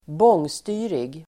Ladda ner uttalet
bångstyrig adjektiv, unruly , disorderly Uttal: [²b'ång:sty:rig] Böjningar: bångstyrigt, bångstyriga Synonymer: besvärlig, bråkig, envis, hårdnackad, motsträvig, omedgörlig, oregerlig, trotsig, vild Definition: bråkig, besvärlig